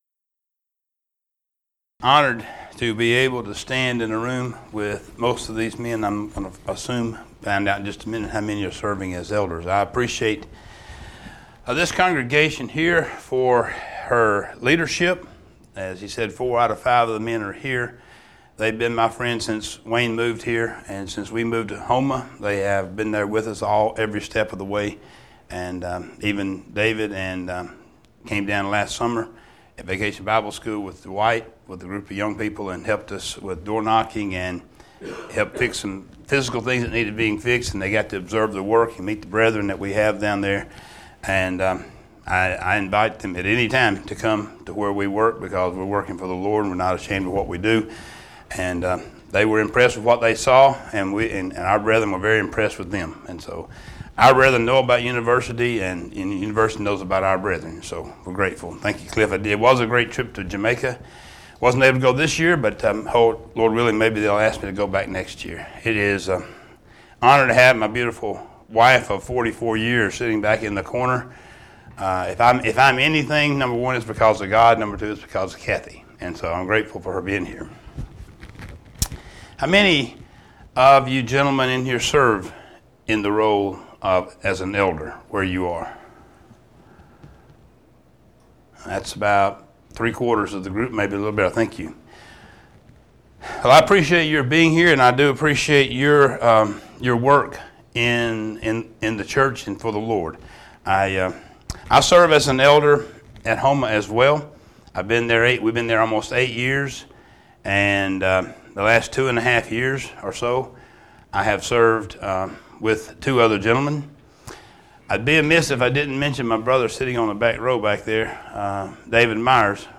Event: 2016 Focal Point Theme/Title: Preacher's Workshop
lecture